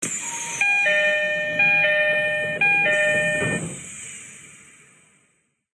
1100DoorClose.ogg